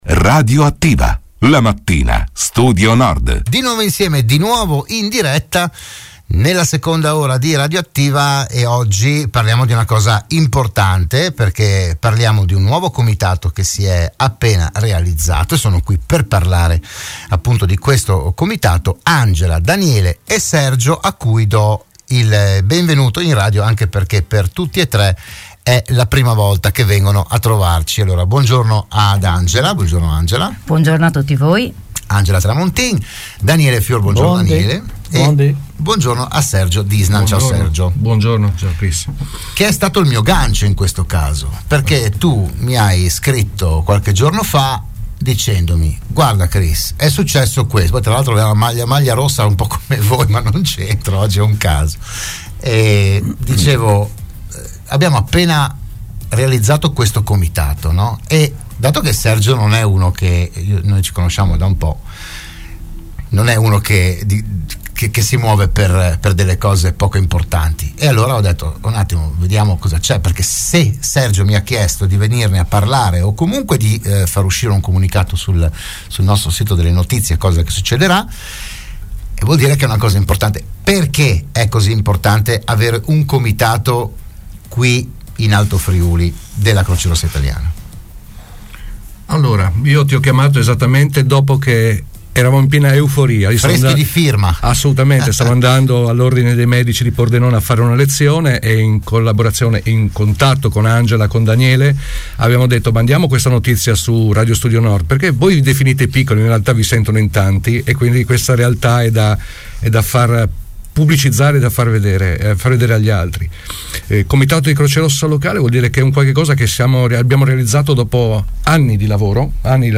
Avrà sede legale a Tolmezzo. Se n'è parlato a "RadioAttiva" di Radio Studio Nord